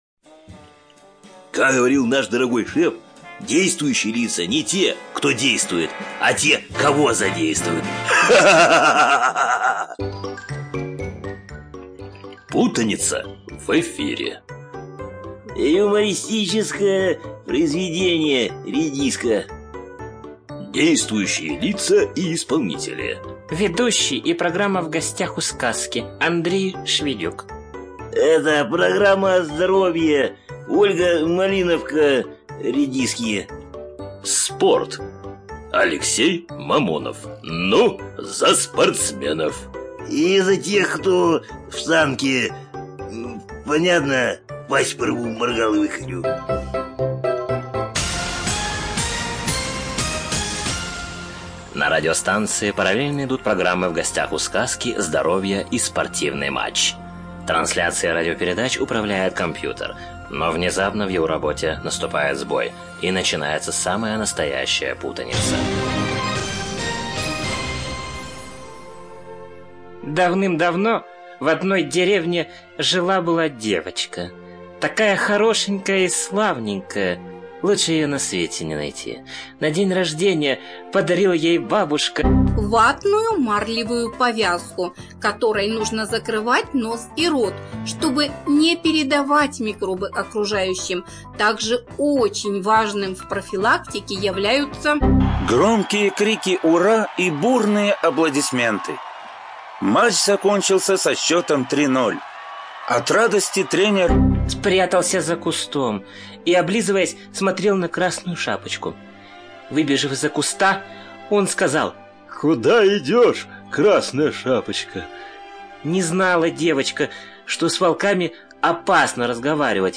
ЖанрЮмор и сатира